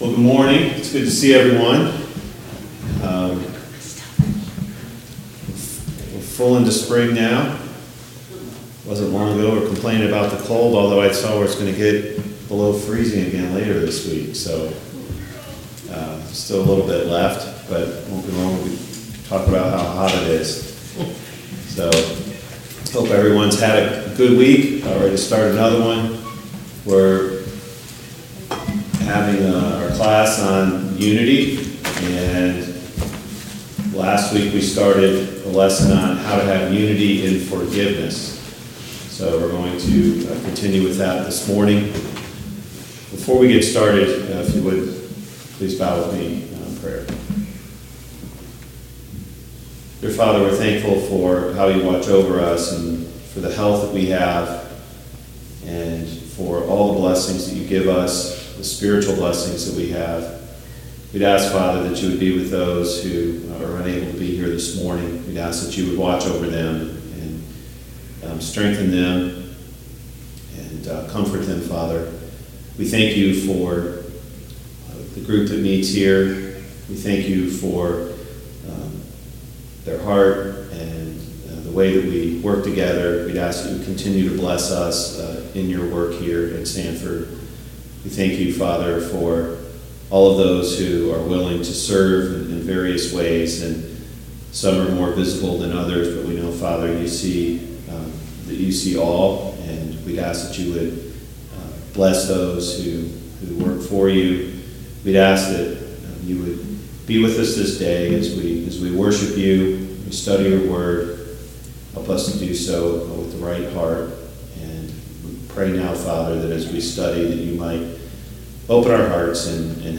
Service Type: Sunday Morning Bible Class Topics: Forgiveness , Grace , Mercy , The Need for Unity in the church , Unity in the Church